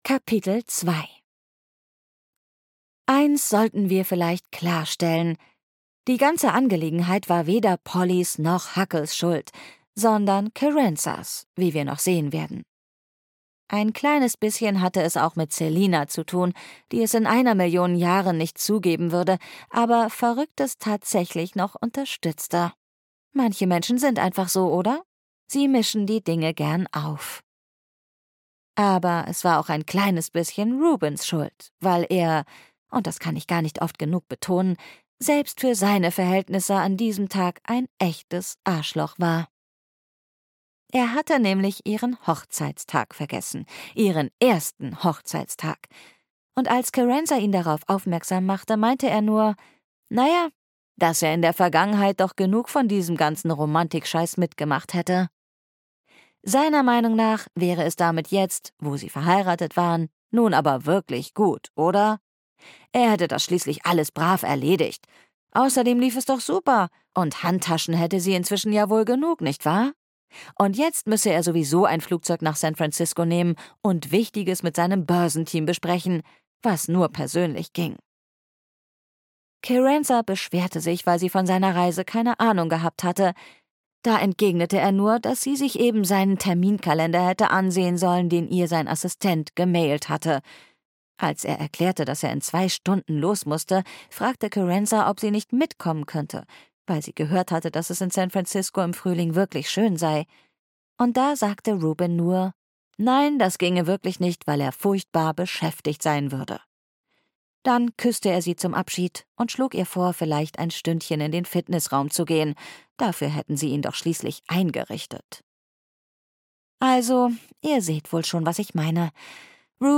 Weihnachten in der kleinen Bäckerei am Strandweg (Die kleine Bäckerei am Strandweg 3) - Jenny Colgan - Hörbuch